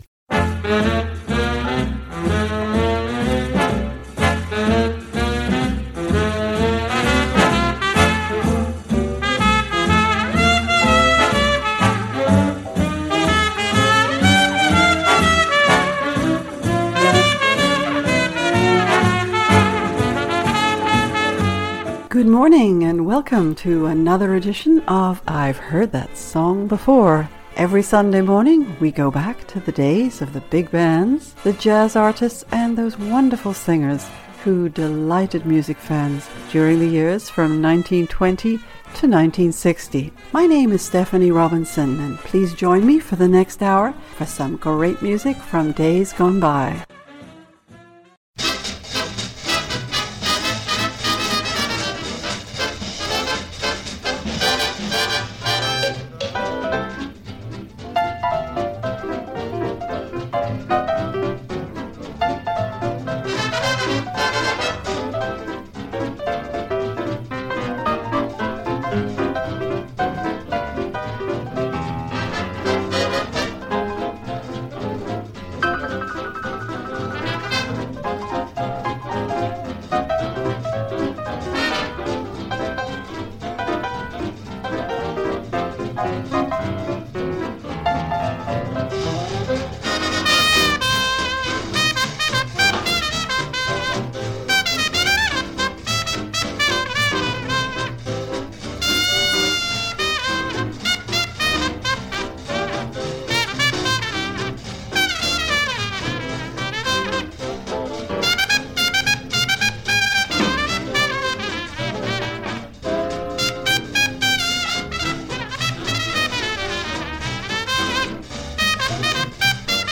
a selection of big bands